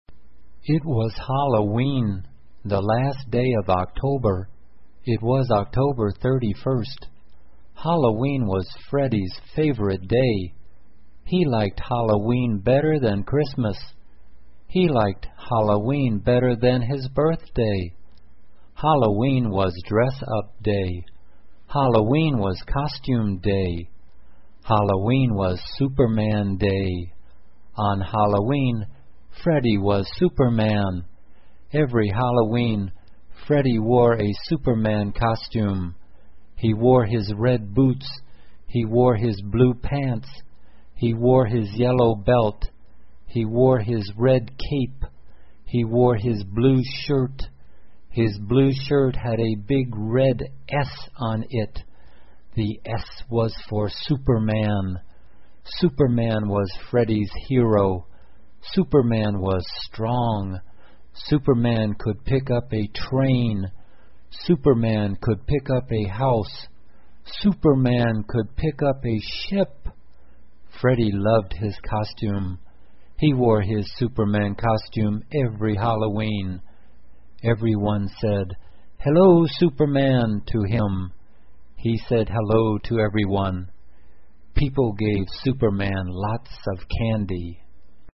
慢速英语短文听力 万圣节 听力文件下载—在线英语听力室